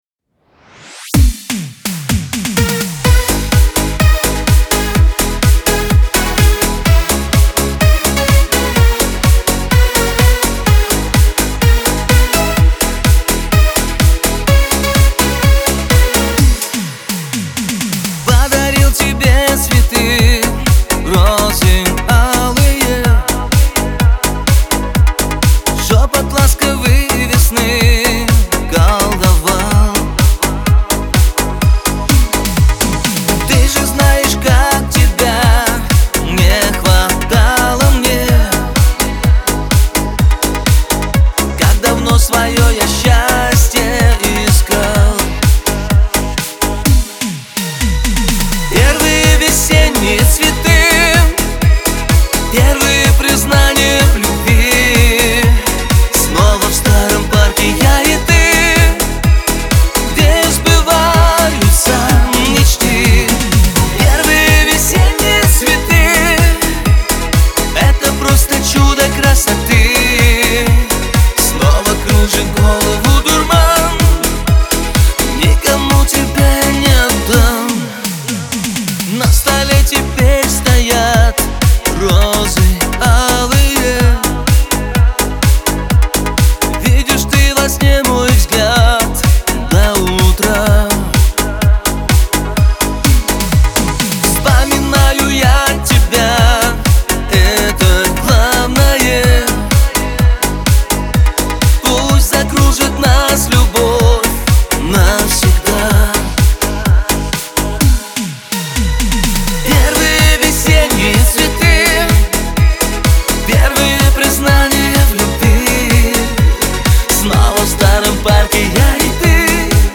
диско , pop